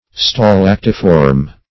Meaning of stalactiform. stalactiform synonyms, pronunciation, spelling and more from Free Dictionary.
Search Result for " stalactiform" : The Collaborative International Dictionary of English v.0.48: Stalactiform \Sta*lac"ti*form\ (-t[i^]*f[^o]rm), a. Like a stalactite; resembling a stalactite.